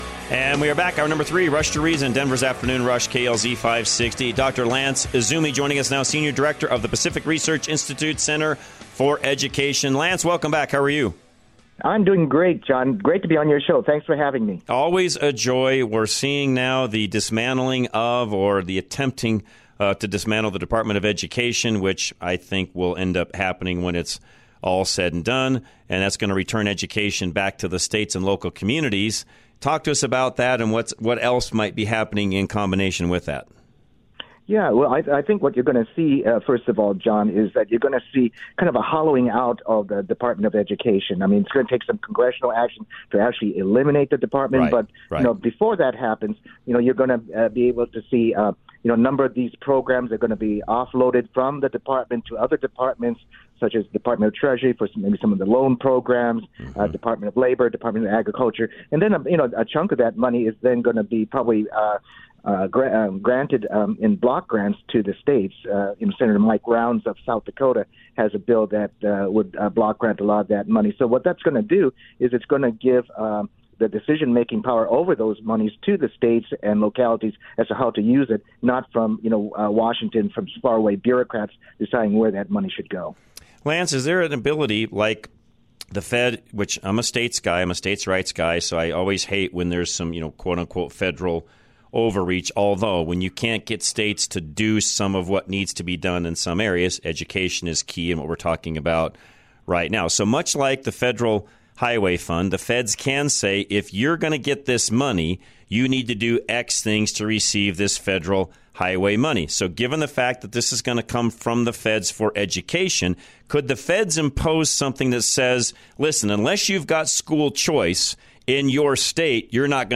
Rush To Reason - Interviews